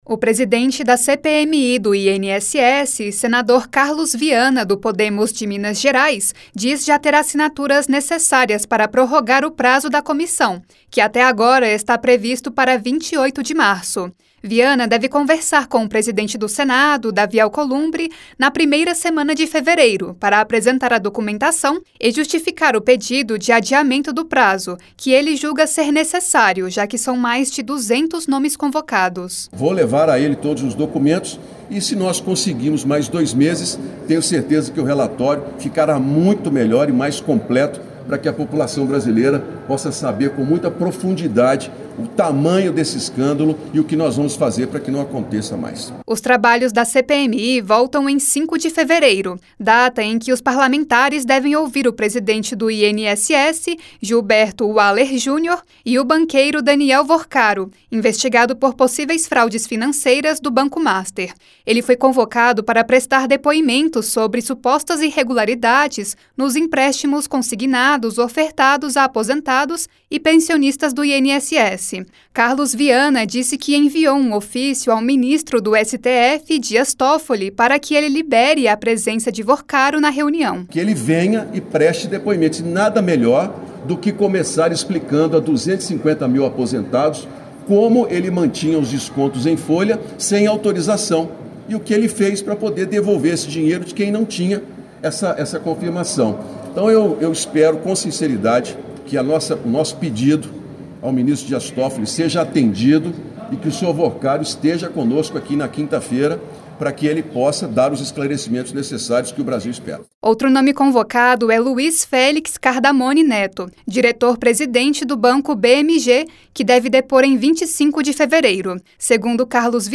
O presidente da CPMI do INSS, Senador Carlos Viana (Podemos-MG) afirmou nesta quinta-feira (29) que irá conversar com o presidente do Senado, Davi Alcolumbre, sobre o pedido de prorrogação de 60 dias da comissão. Em entrevista coletiva, ele reforçou que na próxima quinta-feira (5/2) irão depor o banqueiro Daniel Vorcaro, dono do Banco Master, e o atual presidente do INSS.